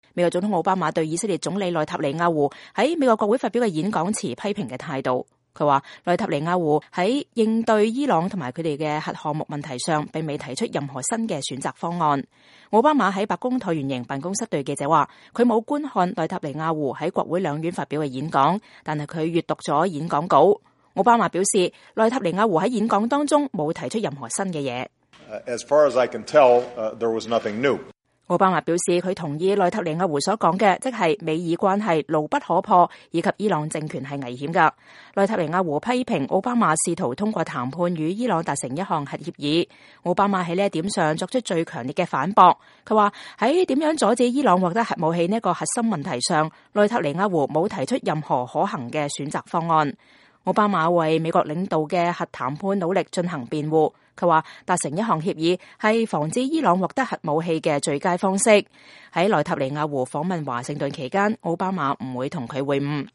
美國總統奧巴馬在白宮講述有關伊朗和以色列總理內塔尼亞胡在美國國會發表演講的問題
奧巴馬在白宮橢圓形辦公室對記者說，他沒有觀看內塔尼亞胡在國會兩院發表的演講，但他閱讀了演講稿。